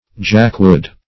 jakwood - definition of jakwood - synonyms, pronunciation, spelling from Free Dictionary Search Result for " jakwood" : The Collaborative International Dictionary of English v.0.48: Jakwood \Jak"wood`\, n. See Jackwood .